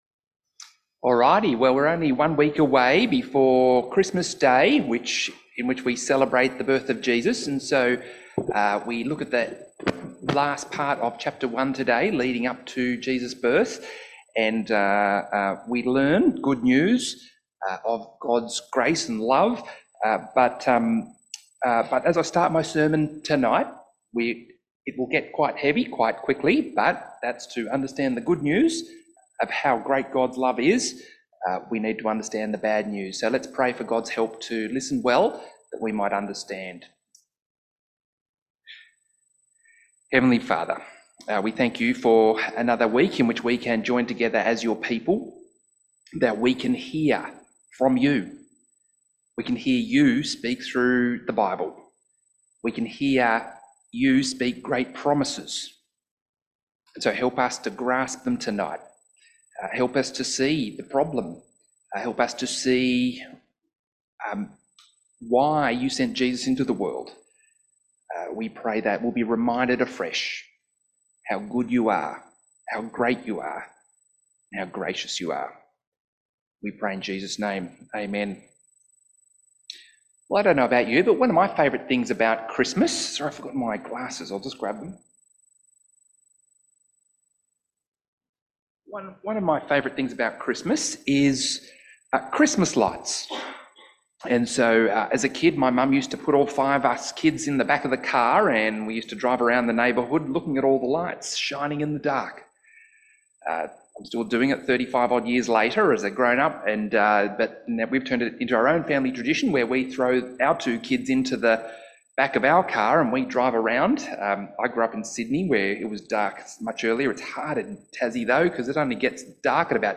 Passage: Luke 1:57-79 Service Type: 5:30pm Resonate Service « Advent 4